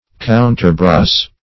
counterbrace - definition of counterbrace - synonyms, pronunciation, spelling from Free Dictionary
Search Result for " counterbrace" : The Collaborative International Dictionary of English v.0.48: Counterbrace \Coun"ter*brace`\, v. t. 1.